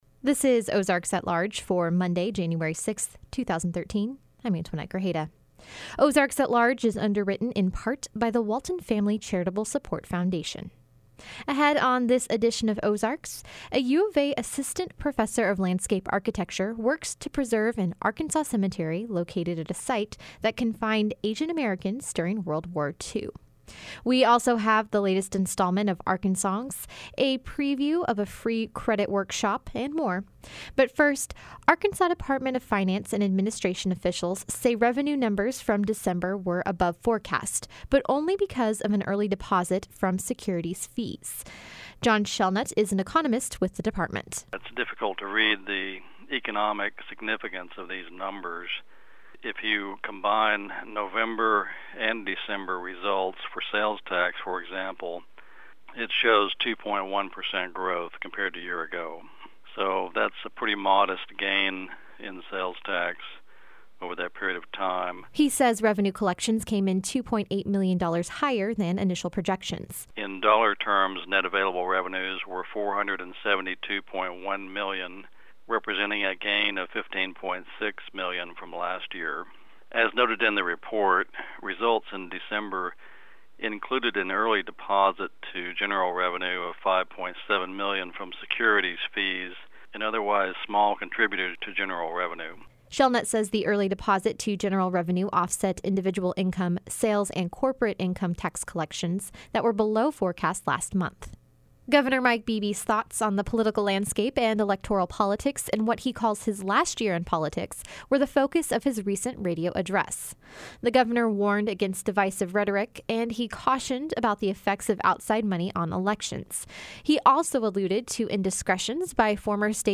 Transition Music: